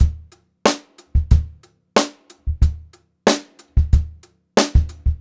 92ST2BEAT1-R.wav